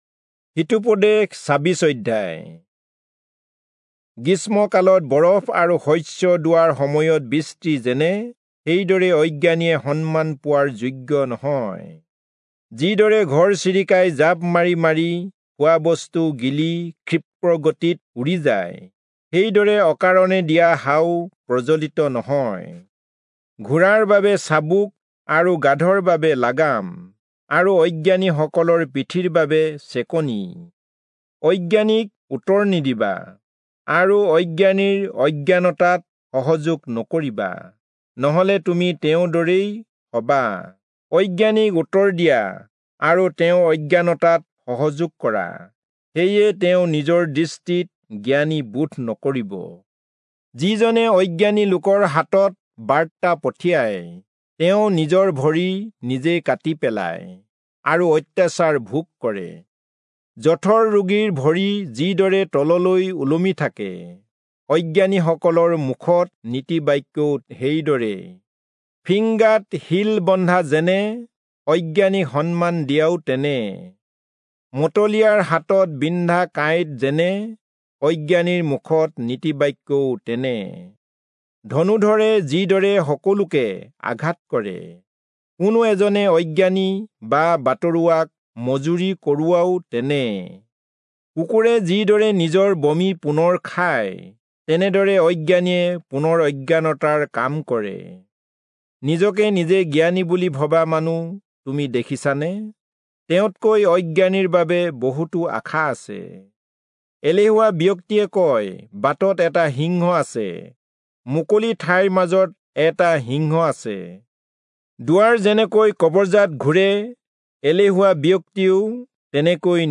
Assamese Audio Bible - Proverbs 31 in Guv bible version